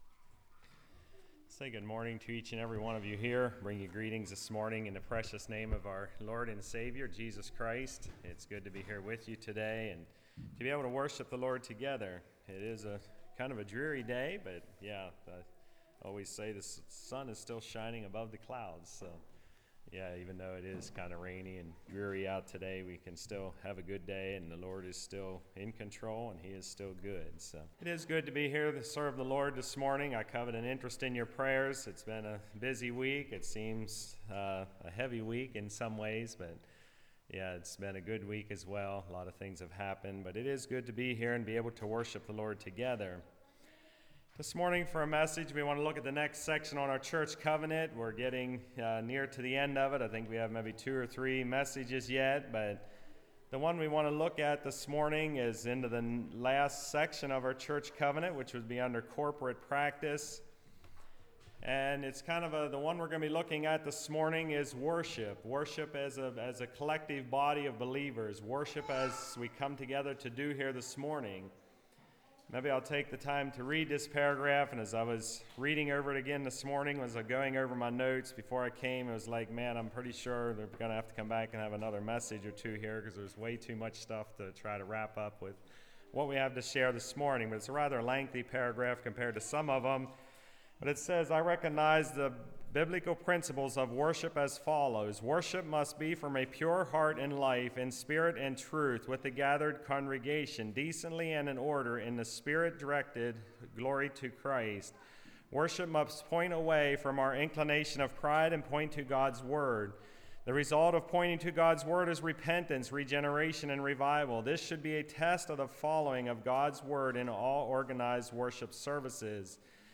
Passage: Genesis 22:1-19 Service Type: Message